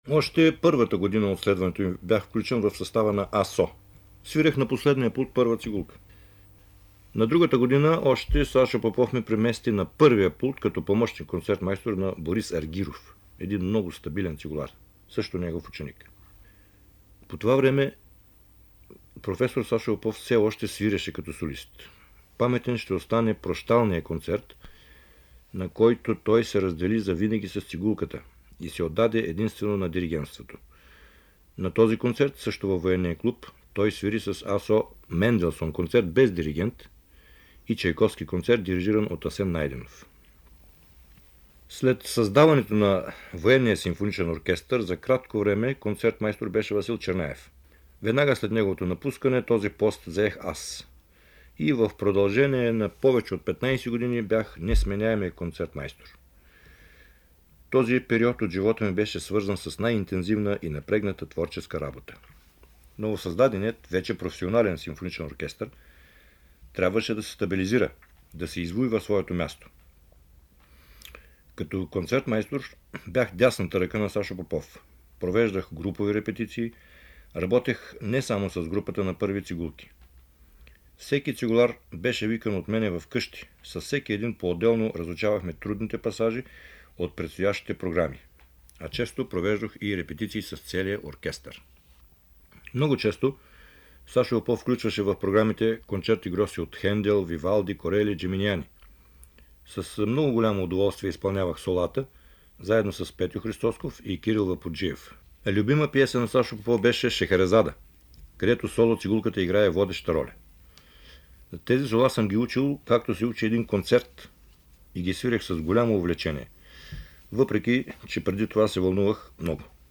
Големият български цигулар и диригент Васил Стефанов, един от многобройните му ученици, започва своя професионален път именно в АСО. За „прощалния концерт“, на който Саша Попов се разделя с цигулката, за работа си с него и за любимите произведения на професора той разказва в запис от 1974 година: